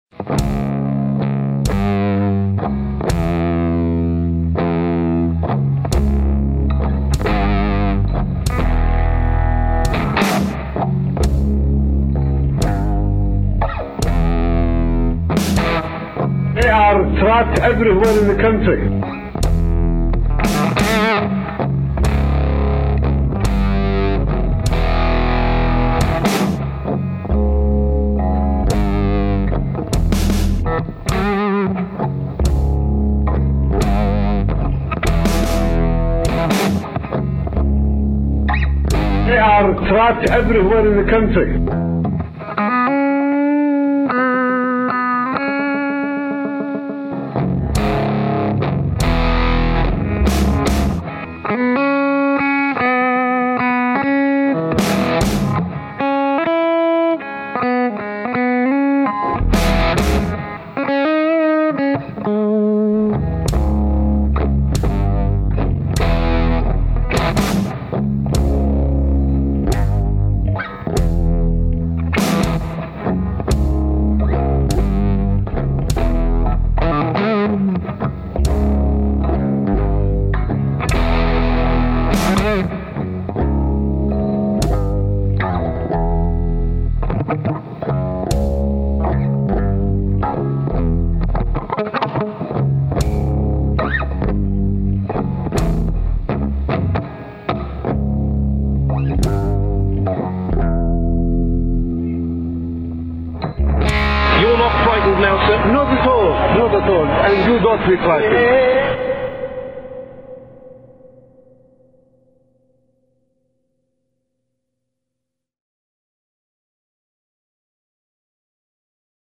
Tunes included for your pleasure, drop ins courtesy of Comical Ali, are "The Faker, The Seer,"